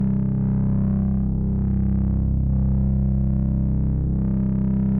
pgs/Assets/Audio/Sci-Fi Sounds/Hum and Ambience/Hum Loop 3.wav at master
Hum Loop 3.wav